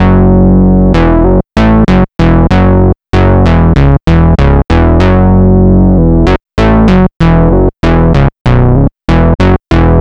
Track 14 - Synth Bass 02.wav